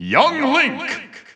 The announcer saying Young Link's name in English and Japanese releases of Super Smash Bros. Ultimate.
Young_Link_English_Announcer_SSBU.wav